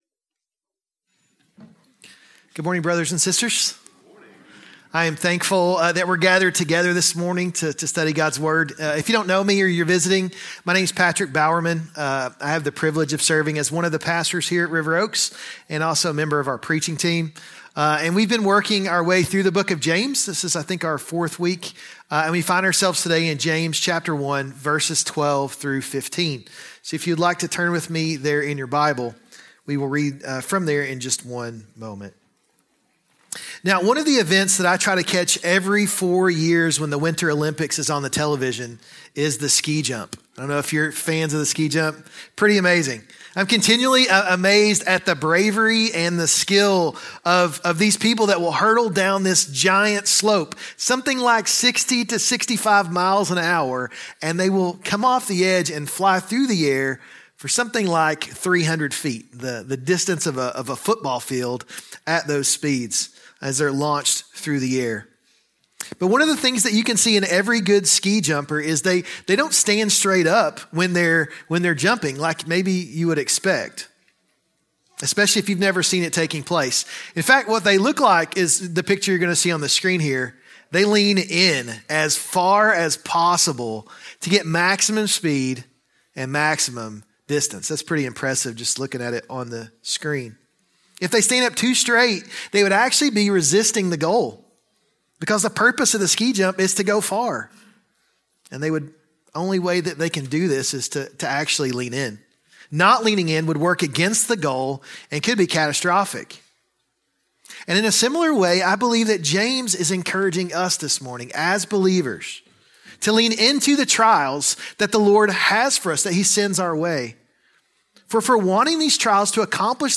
A sermon on James 1:12-15